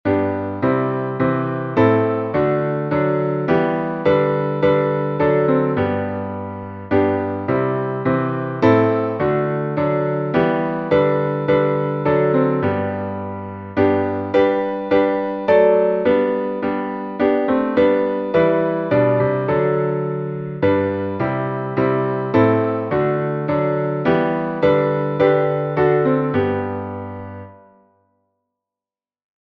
Melodia tradicional galesa
salmo_9B_instrumental.mp3